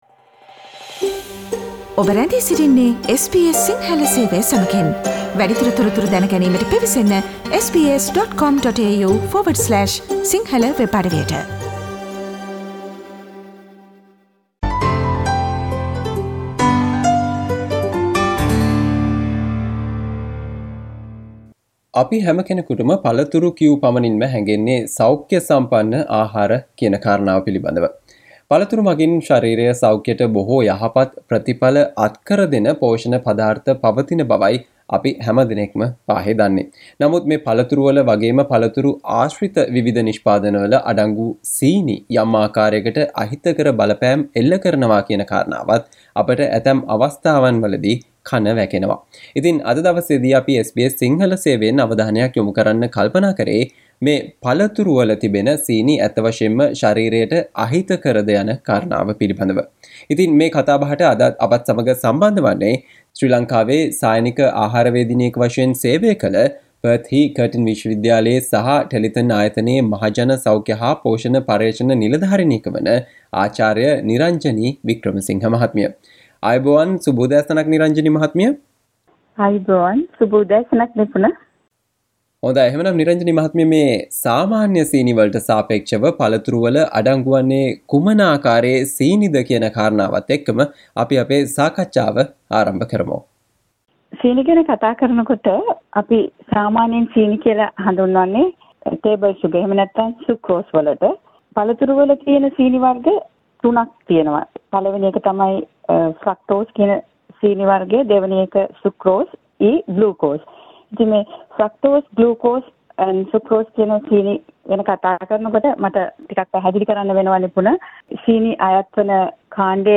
SBS Sinhala Discussion on How the sugar in fruits affects the body and what you need to know about it